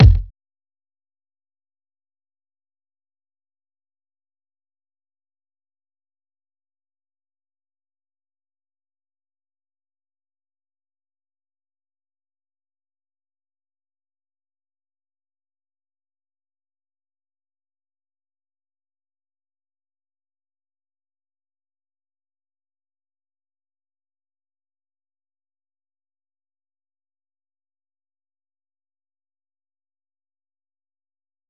Kick